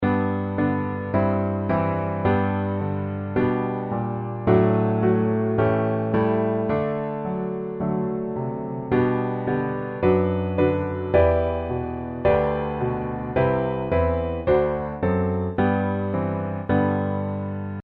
G Major